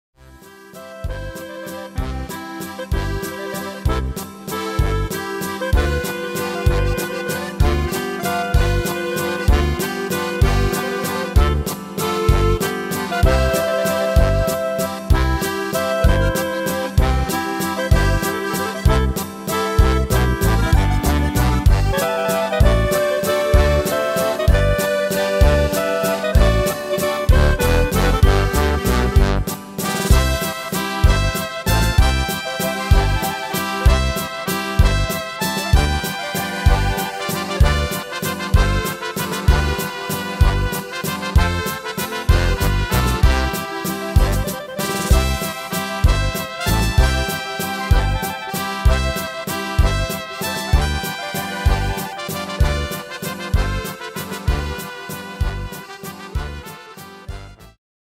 Tempo: 192 / Tonart: C-Dur